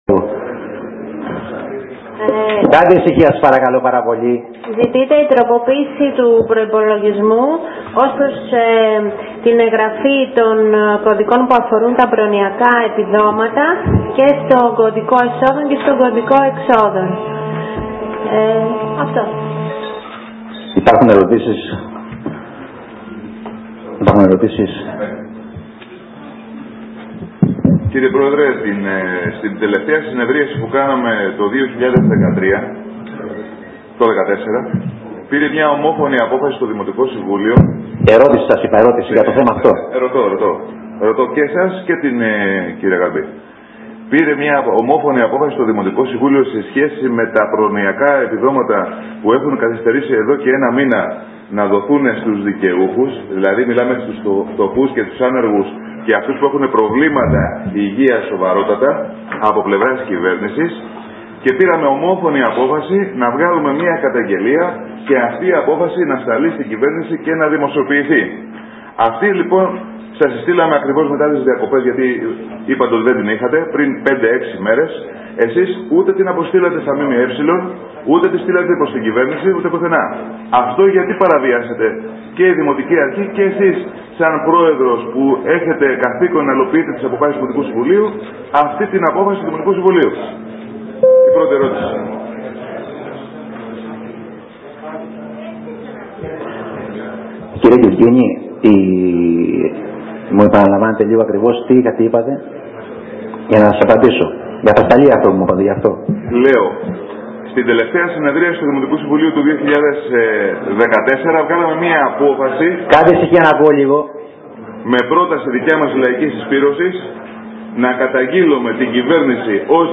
Συνεδρίαση του Δημοτικού Συμβουλίου με πολλές ….ηχηρές απουσίες από την αντιπολίτευση.
Παράλληλα, όπως θα ακούσετε στην ομιλία του Νίκου Γκισγκίνη, έβαλε κι άλλα τρία θέματα, για τα δημοτικά τέλη, τον ΑΠΟΠΛΟΥ και την πεζογέφυρα της Σάμης.